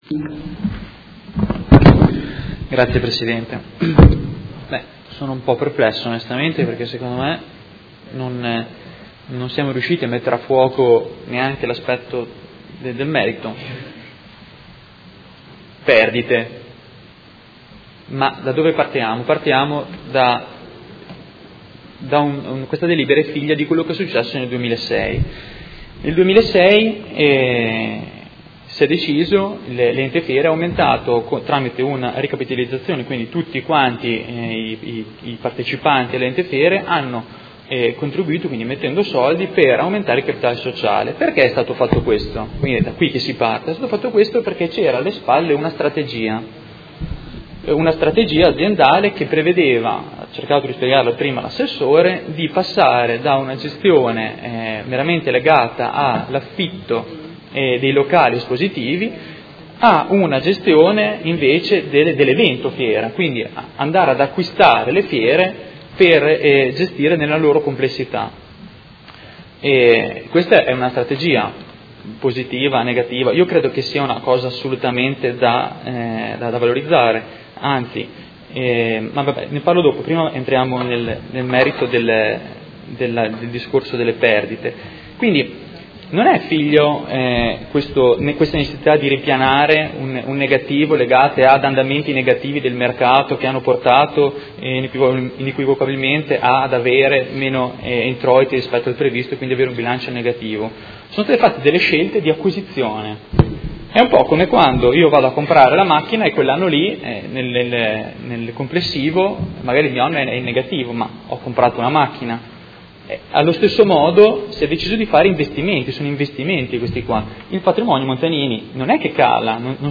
Seduta del 7 luglio. Proposta di deliberazione: Modena Fiere srl – Proposta di riduzione del capitale sociale in conseguenza di perdite a norma dell’art. 2482bis del Codice Civile. Dibattito